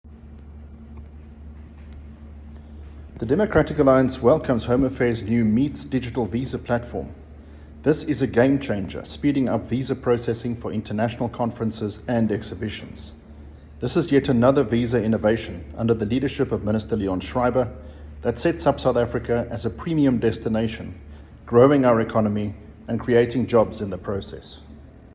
Issued by Adrian Roos MP – DA Spokesperson on Home Affairs
Afrikaans soundbite by Adrian Roos MP.